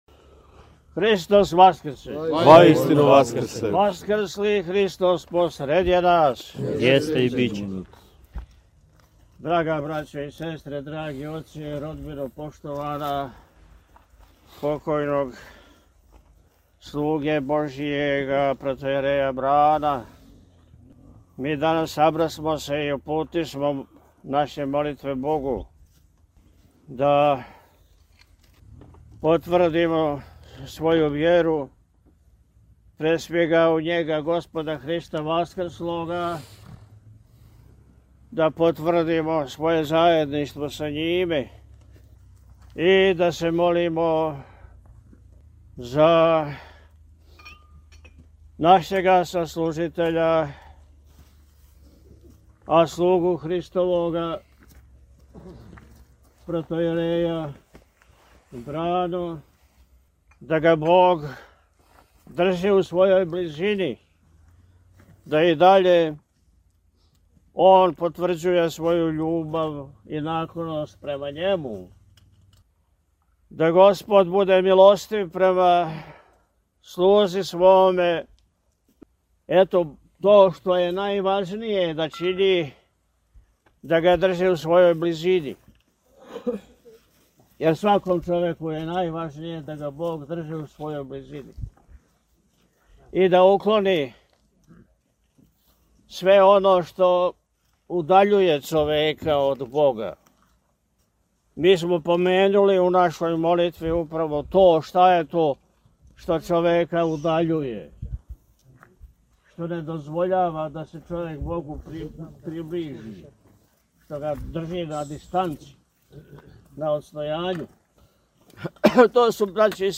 Четрдесетодневни парастос